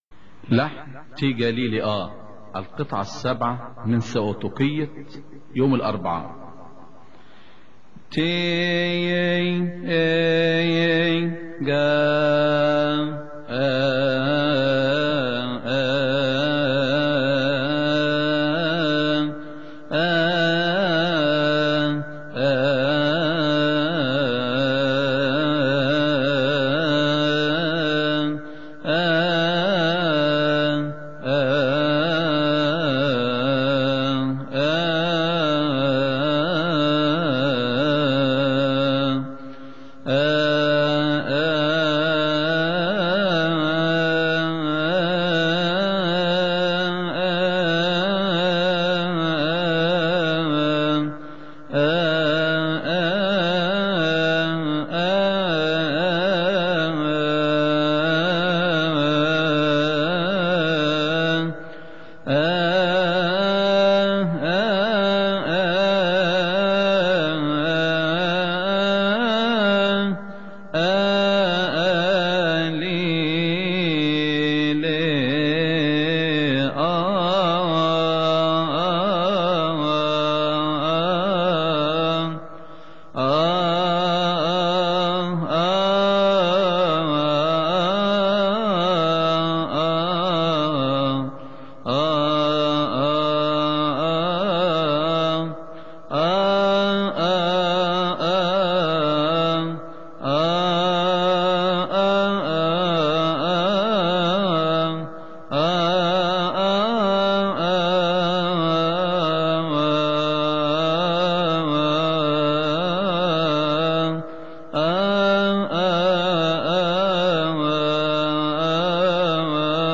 لحن تي جاليلي اي
استماع وتحميل لحن لحن تي جاليلي اي من مناسبة keahk